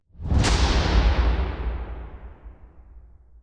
Z SLAM.wav